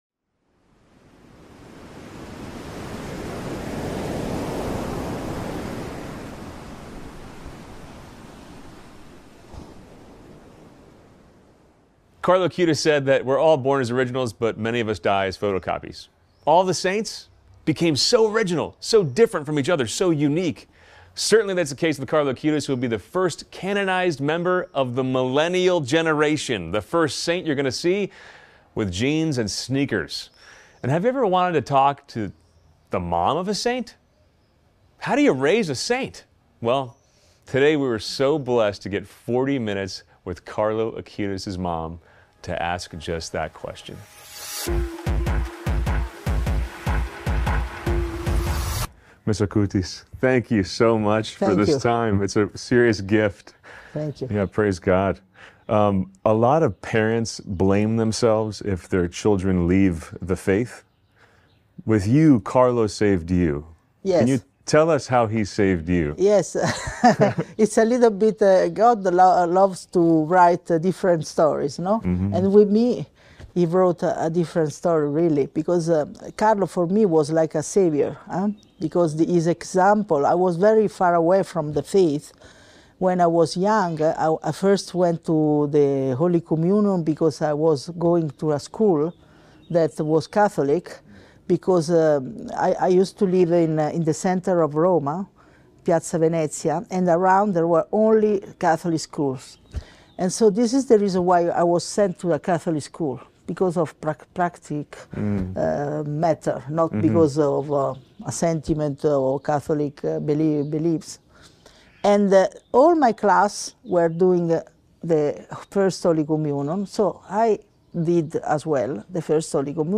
EXCLUSIVE Interview With Bl.